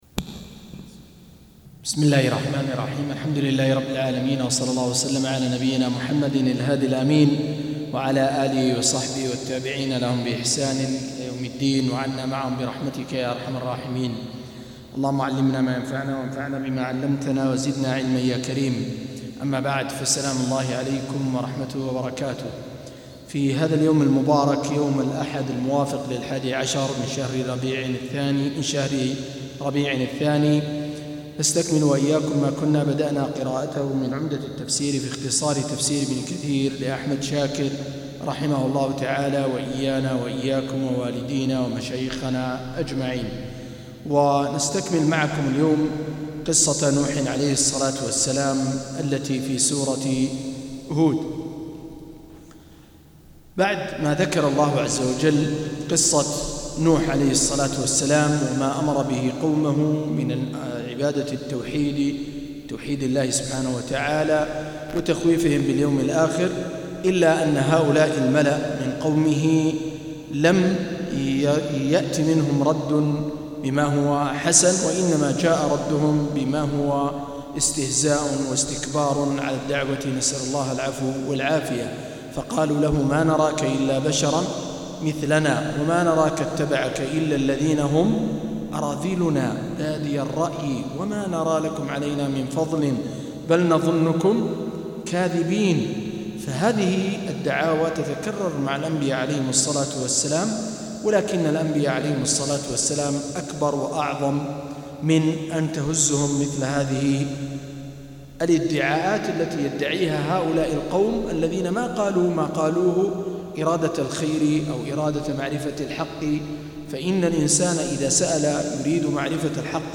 214- عمدة التفسير عن الحافظ ابن كثير رحمه الله للعلامة أحمد شاكر رحمه الله – قراءة وتعليق –